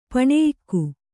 ♪ paṇeyikku